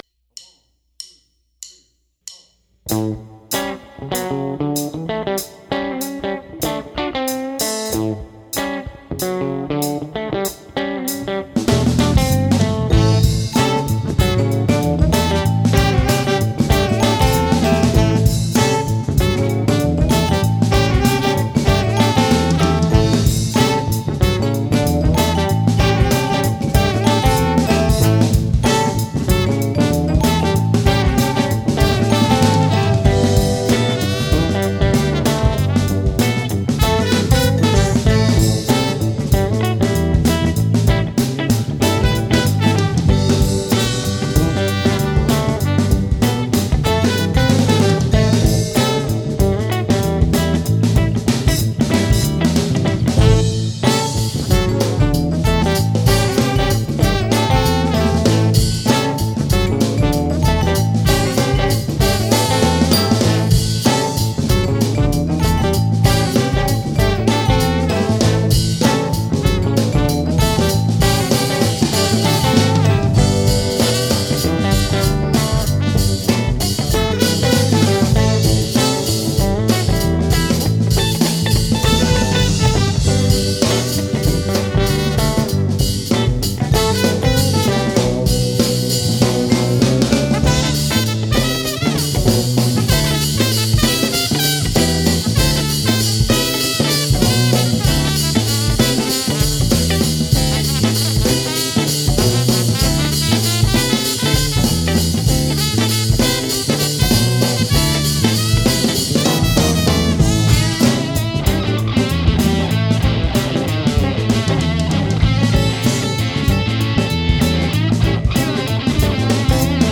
Guitar
Bass
Drums
Vibraphone
Alto Sax
Trumpet